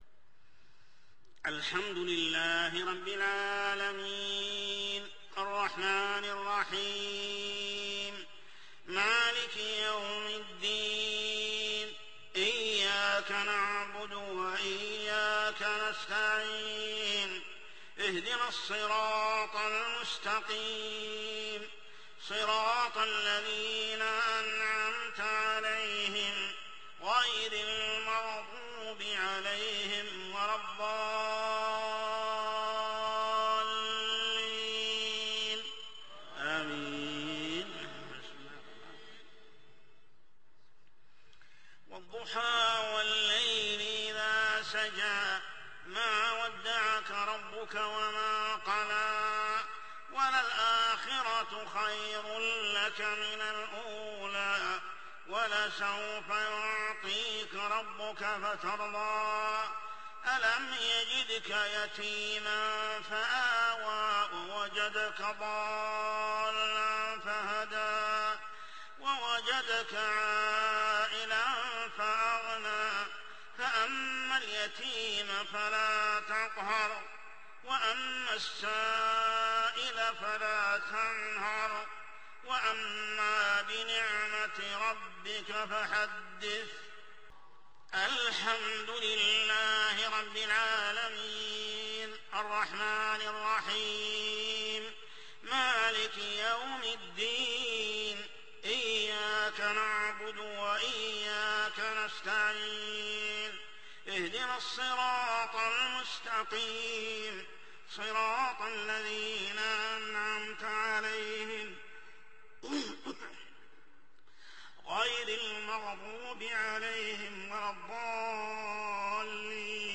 صلاة العشاء عام 1428هـ سورتي الضحى و الشرح كاملة | Isha prayer Surah Ad-duha and Ash-Sharh > 1428 🕋 > الفروض - تلاوات الحرمين